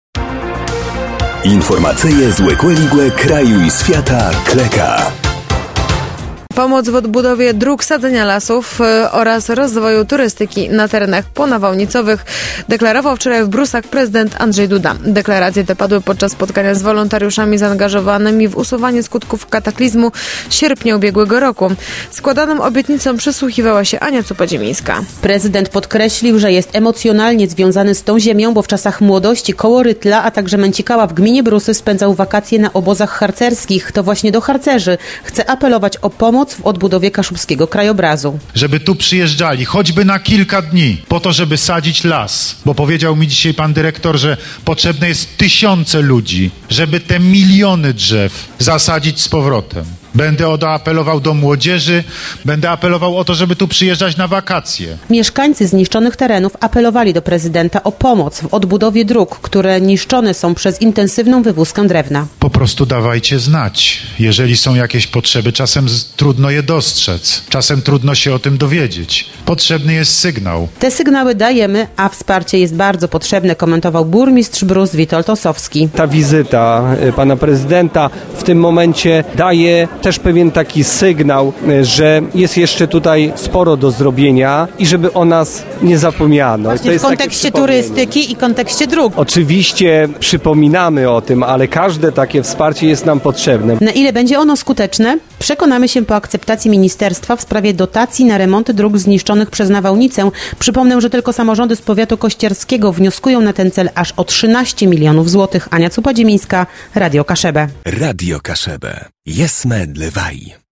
Deklaracje te padły podczas spotkania z wolontariuszami zaangażowanymi w usuwanie skutków kataklizmu z sierpnia ubiegłego roku.